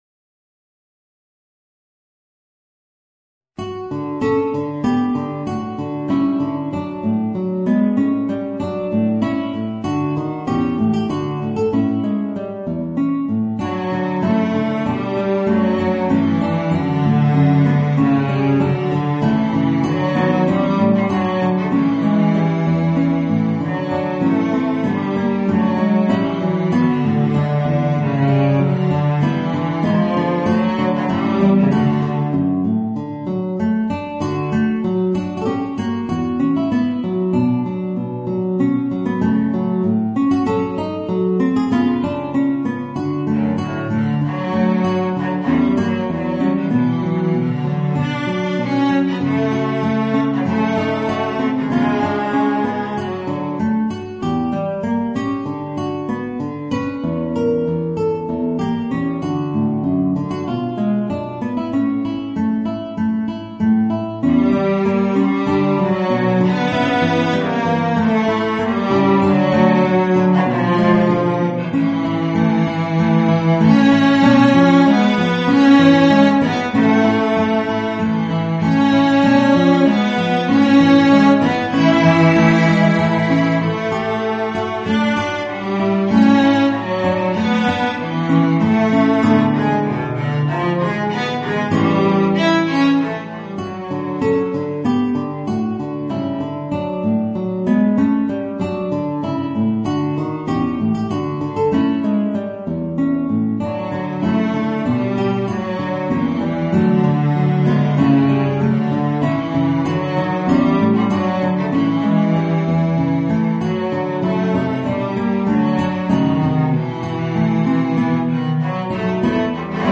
Voicing: Violoncello and Guitar